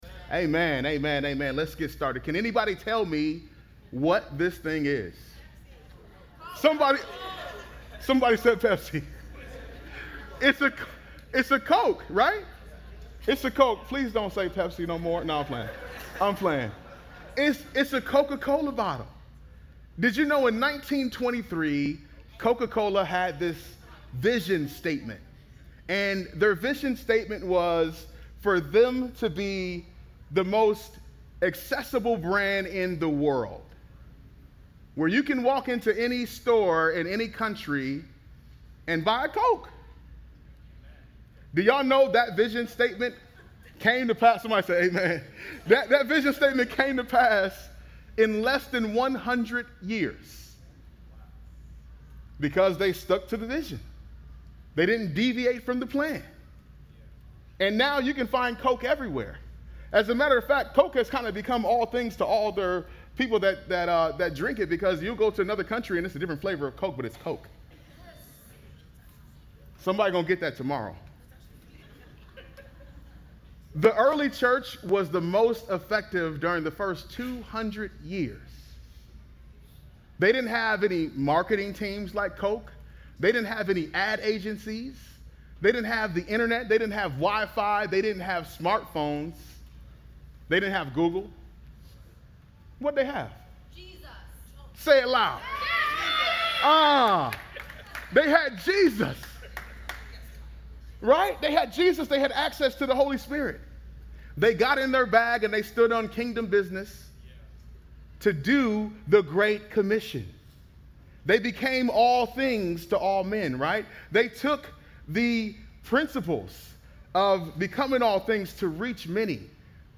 brings today's sermon on 2 Timothy 1.&nbsp; 2 Timothy 1:1-13